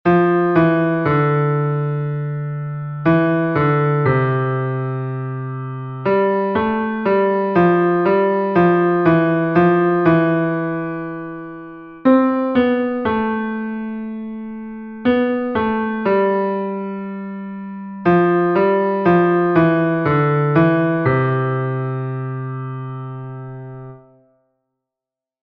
Here, there are two exercises in a 6/8 time signature.
keeping the beat exercise 2